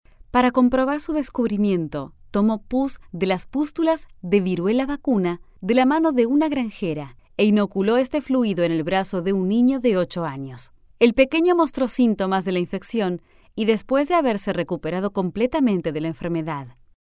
Latin American female voice overs